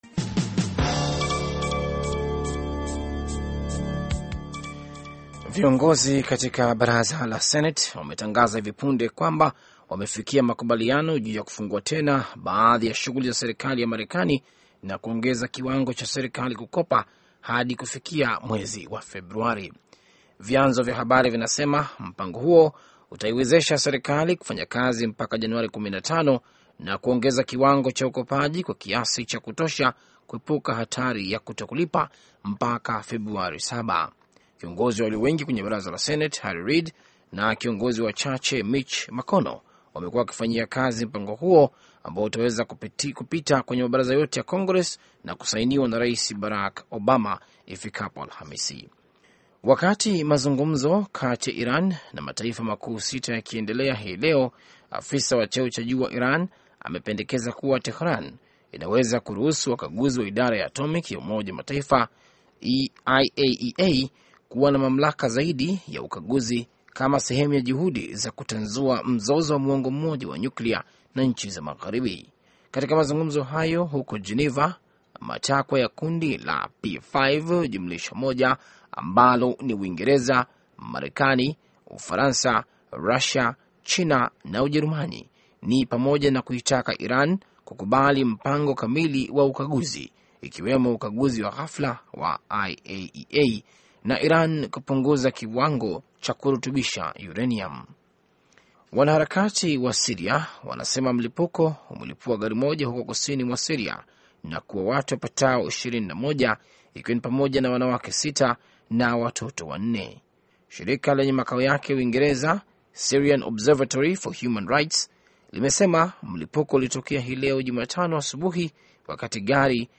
Taarifa ya Habari VOA Swahili - 5:45